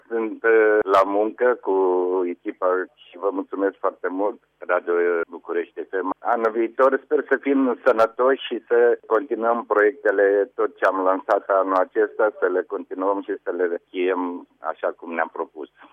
AUDIO: Interviu in exclusivitate cu Ivan Patzaichin, unul dintre cei mai mari sportivi romani, cel care continua alaturi de BucurestiFM proiectele menite sa ne ajute sa ducem o viata mai sanatoasa.